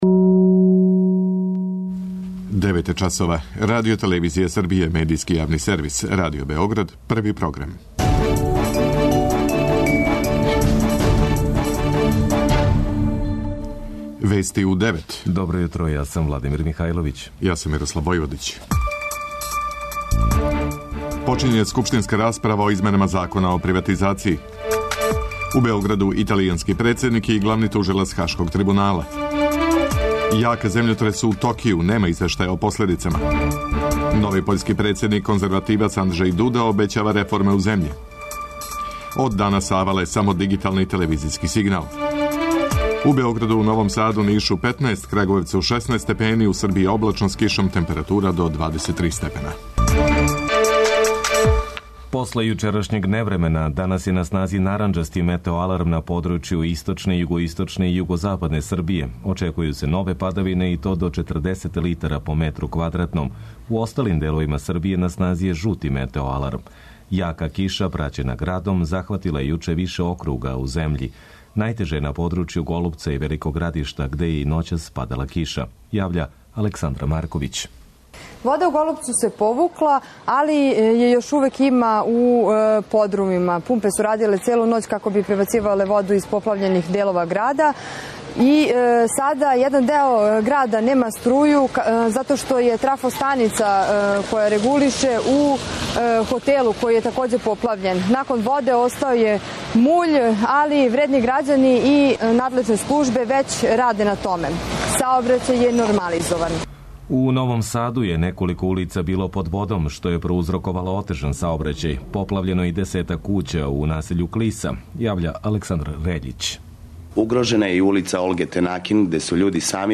преузми : 10.73 MB Вести у 9 Autor: разни аутори Преглед најважнијиx информација из земље из света.